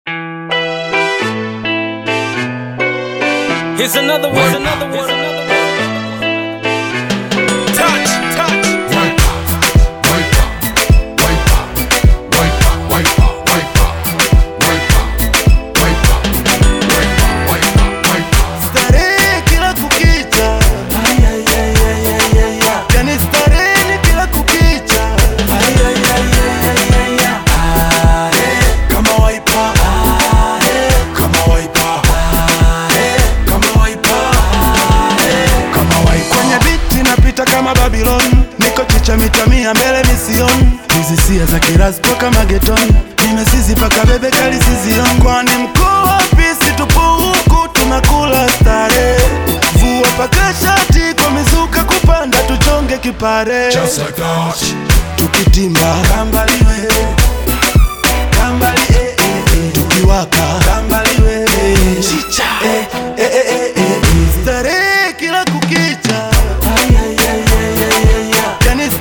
Bongo Fleva